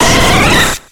Cri de Voltali dans Pokémon X et Y.